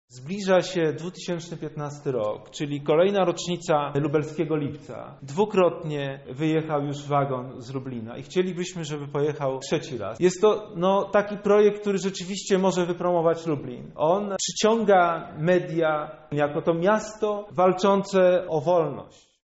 Konferencja
Konferencja.mp3